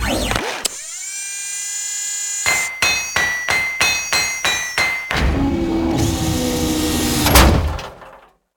cargodrone.ogg